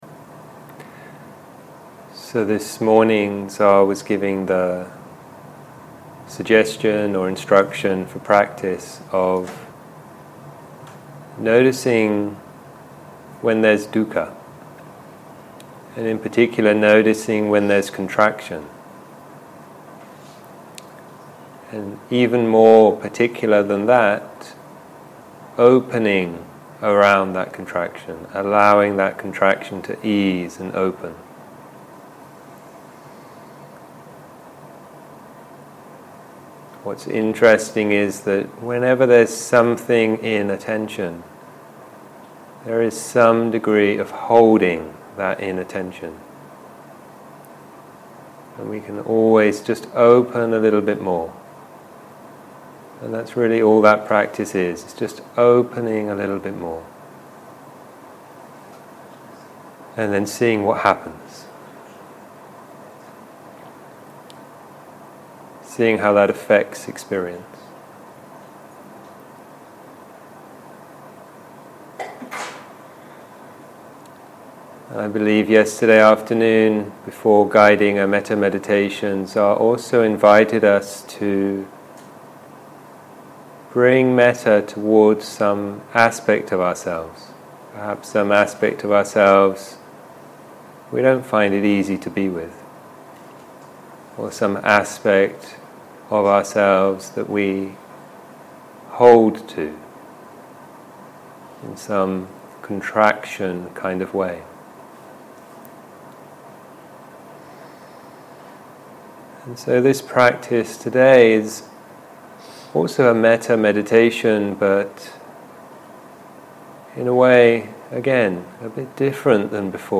Dharma type: Guided meditation